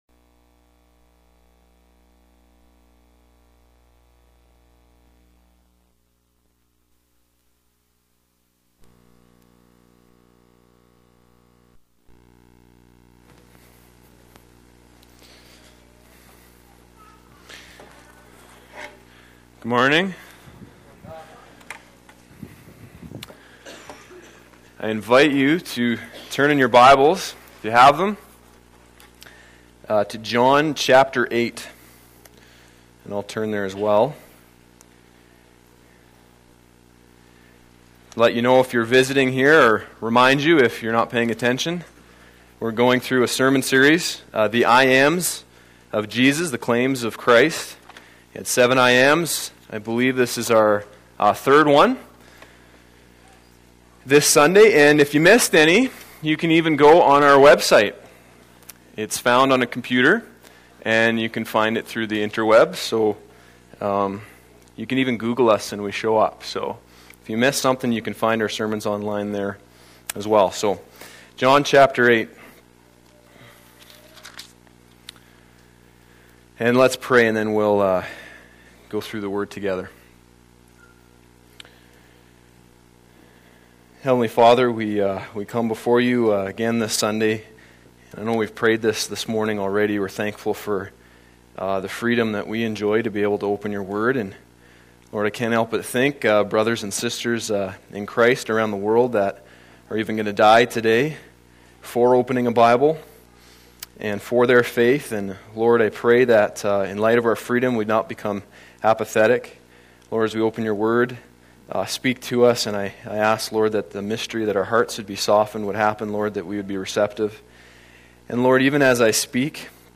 Sermons Archive - Page 55 of 58 - Rocky Mountain Alliance Church